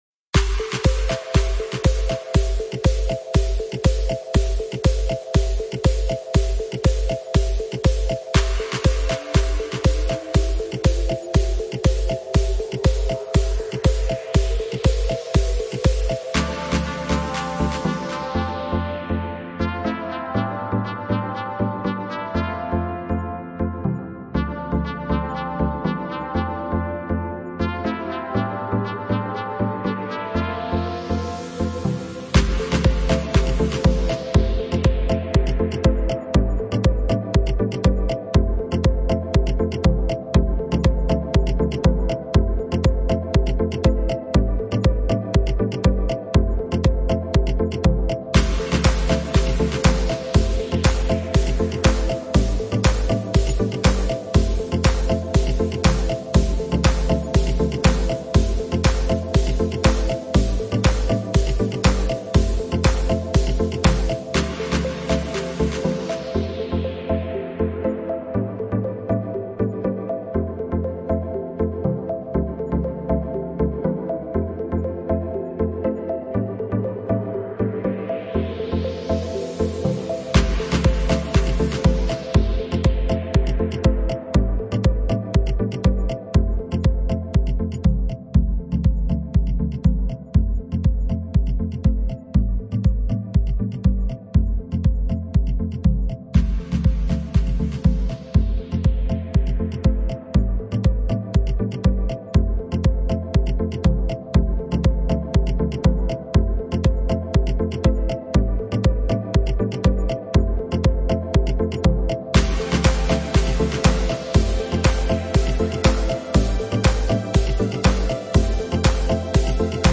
Música-para-hacer-ejercicio-en-casa-rutina-30-minutos-2020-Gym-1-.mp3